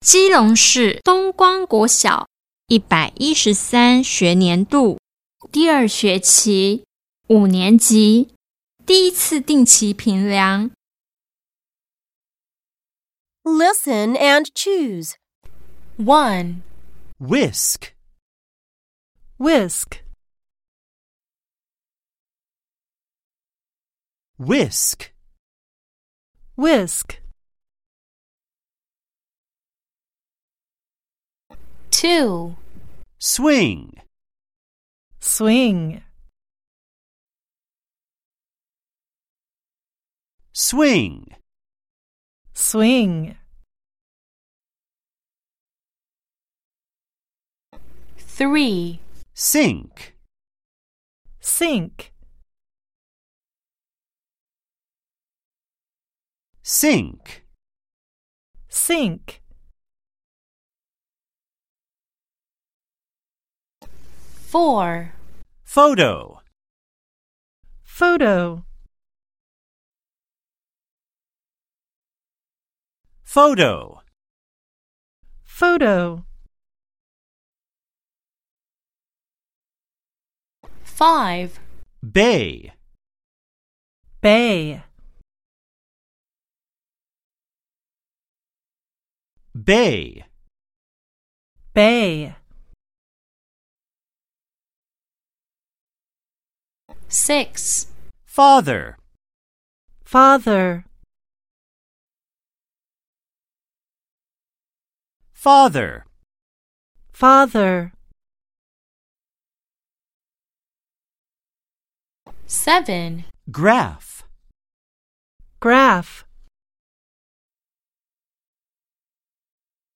第1次評量試題_英語聽力測驗.mp3